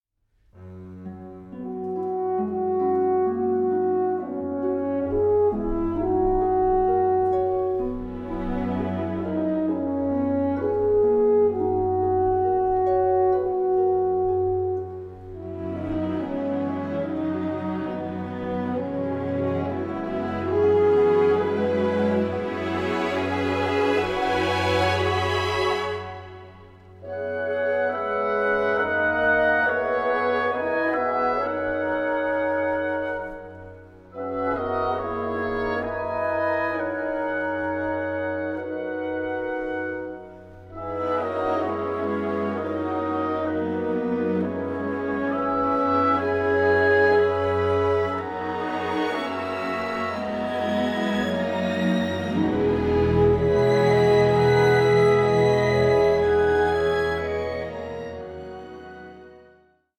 A SUBLIME FUSION OF JAZZ MASTERY AND ORCHESTRAL ELEGANCE
Recorded live